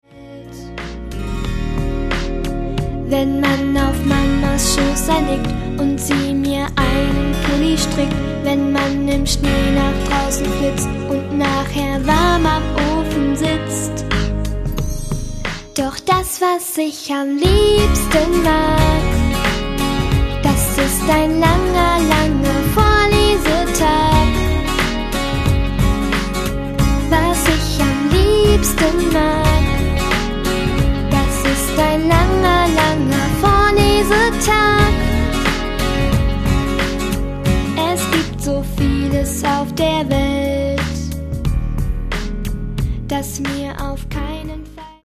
Die schönsten Gute-Nacht-Lieder
• Sachgebiet: Kinderlieder